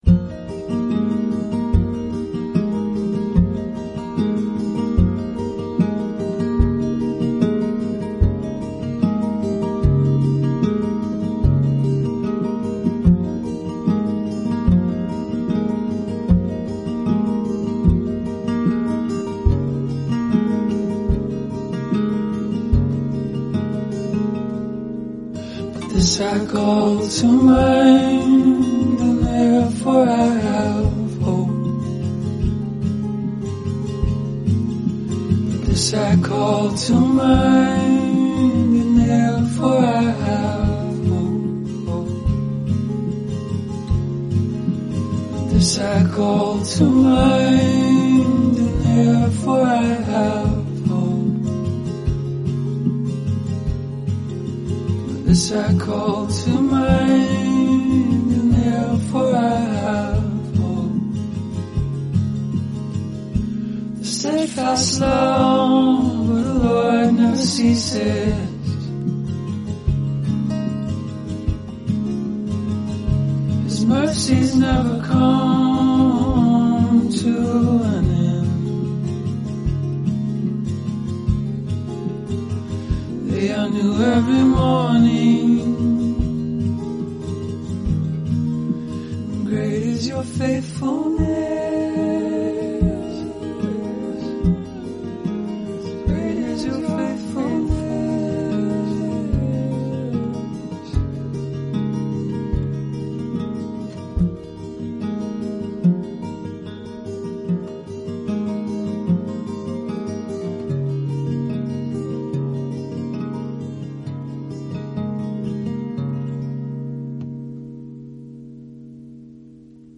word-for-word Scripture song